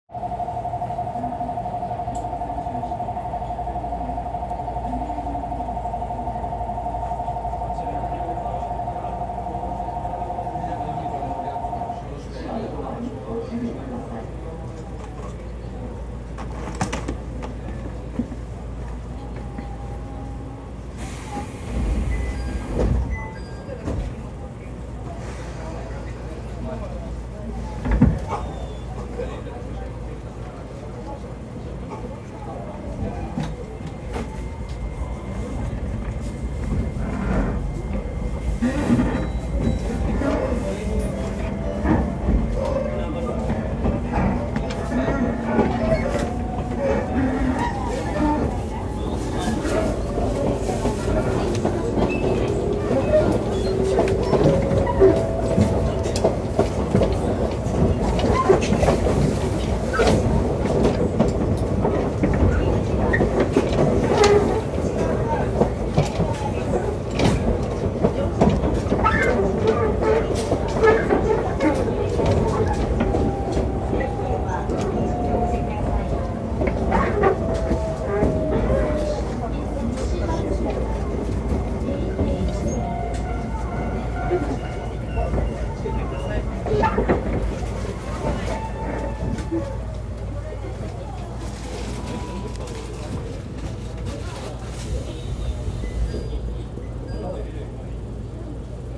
・20100形走行音
【上高地線】松本→西松本
混んでいたわ速度も出ていないわ車内チャイムも流れるわで碌な走行音ではありませんが、一応凡な東洋IGBTであることは伝わるのではないかと思います。とはいえ、出元の車両ゆえか、転調して以降のモーター音は少し面影を残しているようにも聞こえます。